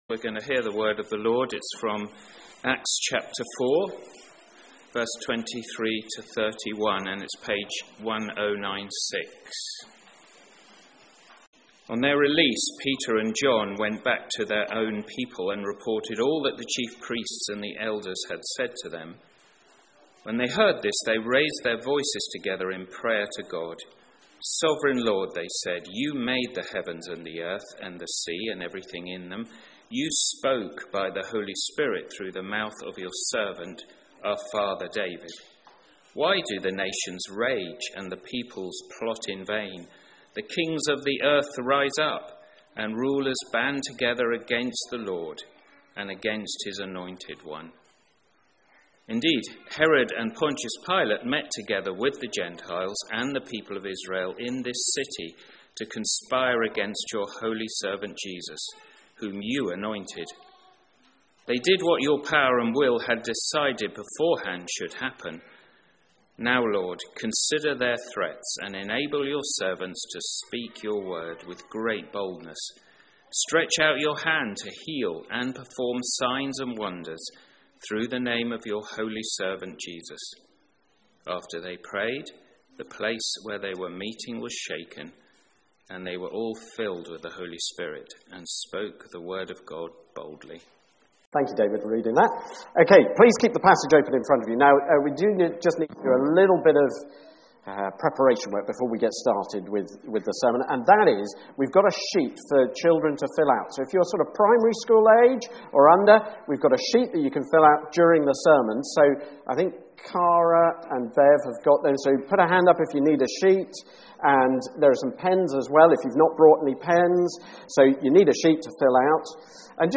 Sermon (Part 1)